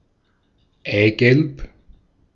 Ääntäminen
IPA : /joʊk/